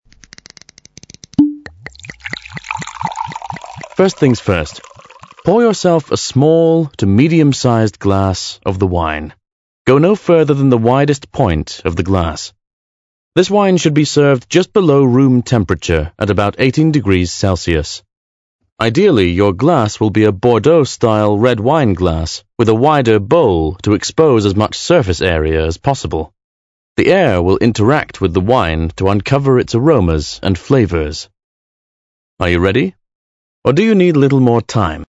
• the power of audio (ASMR + Voice Acting + Soundscapes)
3.2_ENG_12RED_POUR.mp3